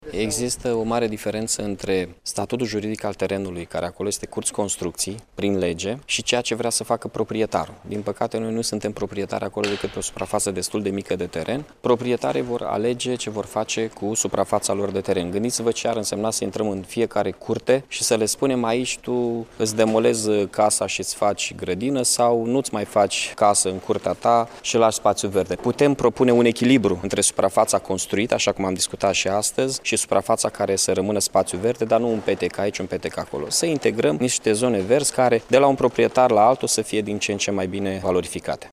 În ceea ce priveşte posibilitatea construirii unor imobile în parcul din faţa fostului sediu al Universităţii Petre Andrei din Iaşi, Mihai Chirica a arătat că acolo sunt persoane private care deţin titluri de proprietate.